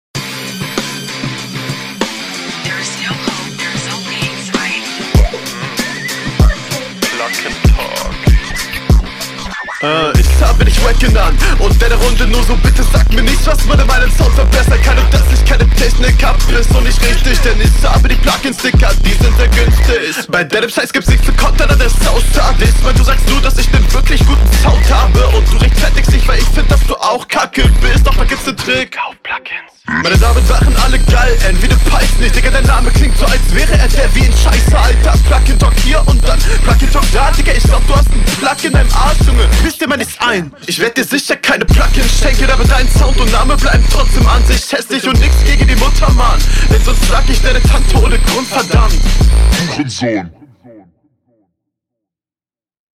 Schöner Flow und gut gekontert.
flowlich ist es viel stabiler, auch cool gekontert. plugin konter ist auch sehr stabil!! reime …